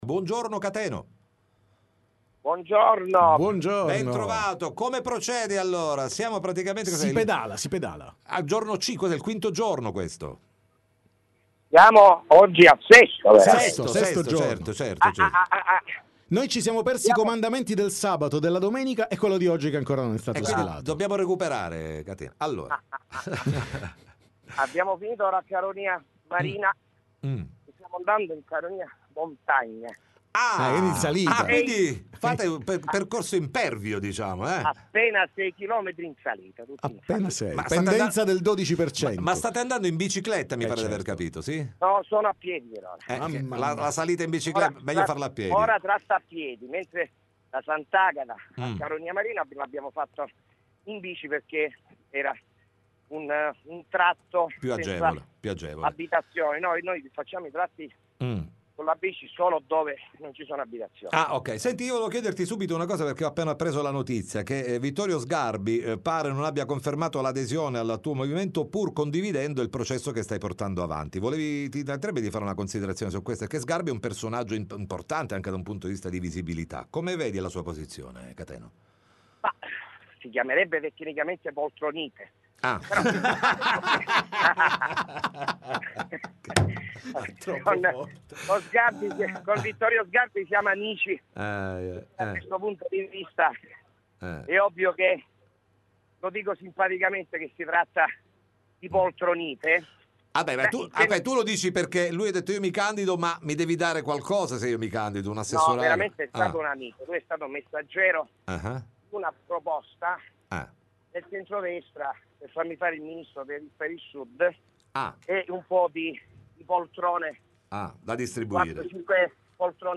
TM Intervista Cateno De Luca